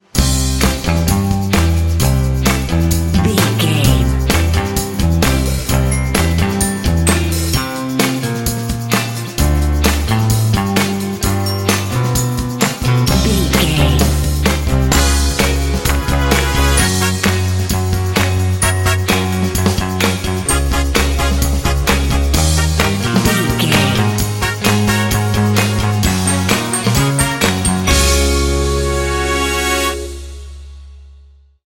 Ionian/Major
energetic
playful
lively
cheerful/happy
piano
trumpet
electric guitar
brass
percussion
bass guitar
drums
rock
classic rock